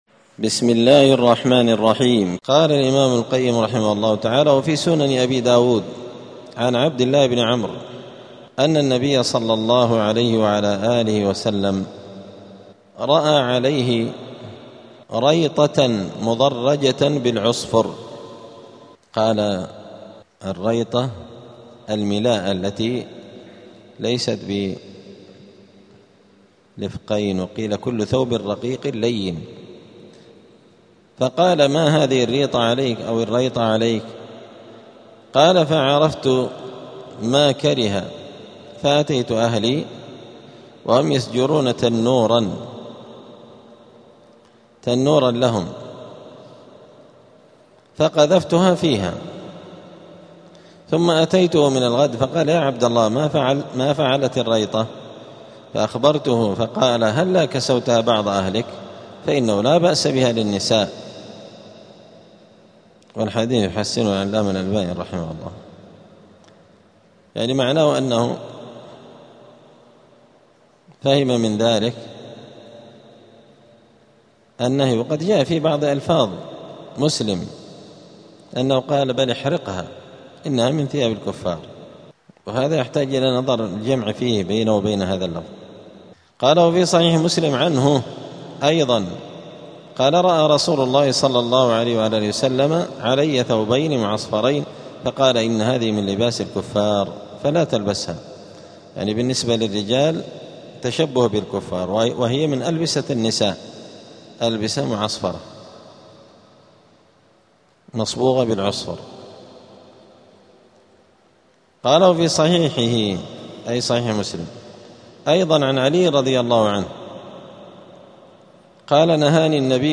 *الدرس الرابع والعشرون (24) {ﻓﺼﻞ ﻓﻲ ملابسه ﺻﻠﻰ اﻟﻠﻪ ﻋﻠﻴﻪ ﻭﺳﻠﻢ}.*